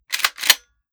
12ga Pump Shotgun - Pump 003.wav